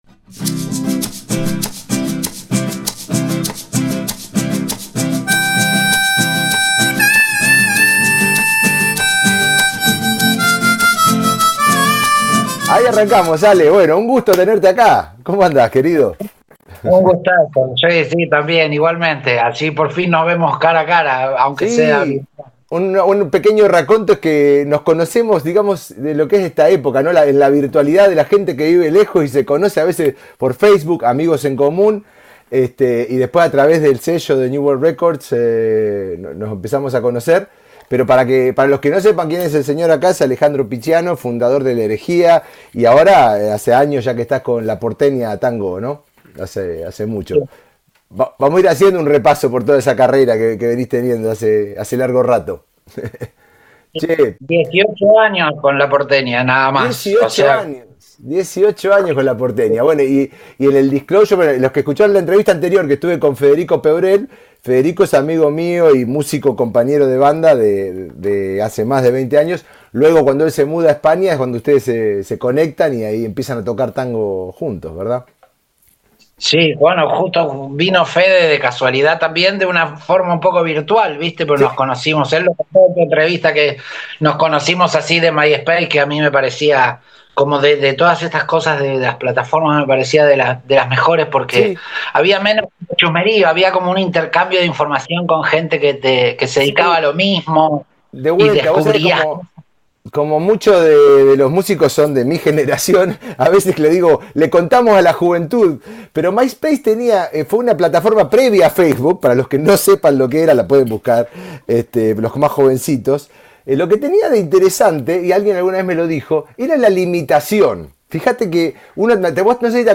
A lo largo de esta charla, conversamos sobre el proceso creativo, el vínculo entre palabra y música, la composición como herramienta expresiva y el rol del director musical dentro de proyectos con una fuerte identidad estética.